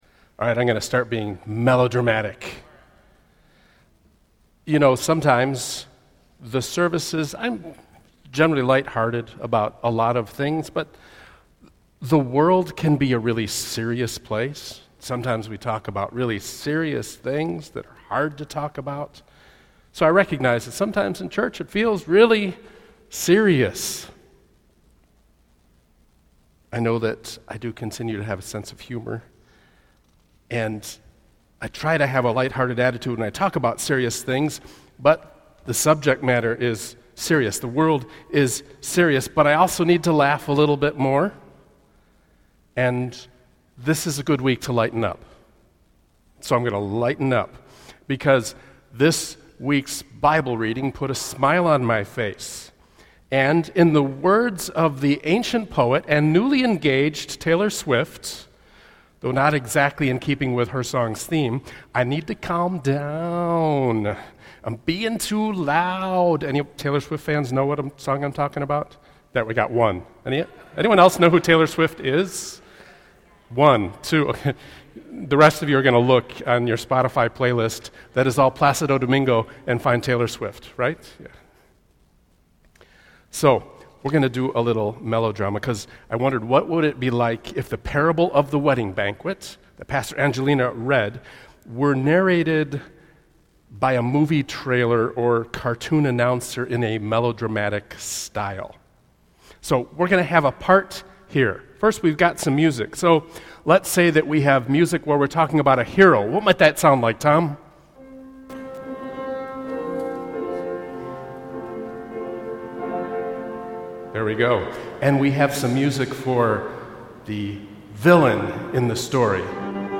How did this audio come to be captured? Sermon podcast from the Sunday, 8-31-25 worship service - Peace Memorial Church, UCC in Palos Park, IL.